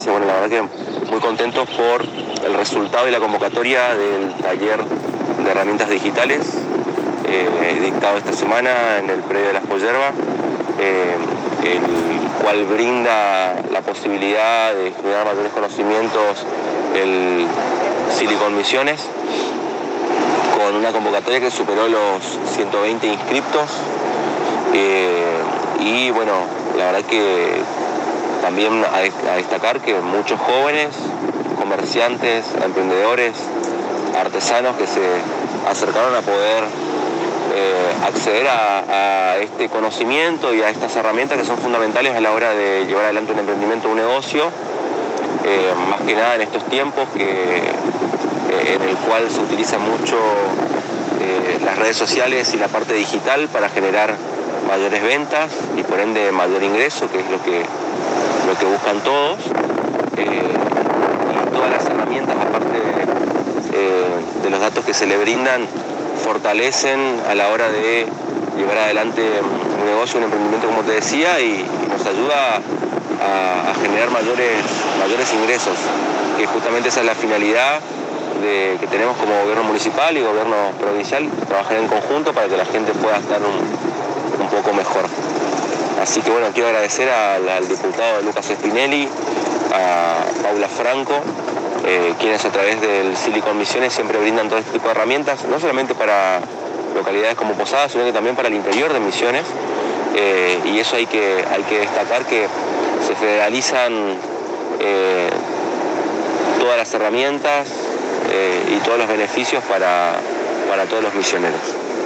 El Director de la Juventud de Apóstoles Aldo Muñoz en diálogo exclusivo con la ANG manifestó su alegría por la realización del Taller de Herramientas digitales que tuvo una gran convocatoria y excelente resultado en la Ciudad de Apóstoles.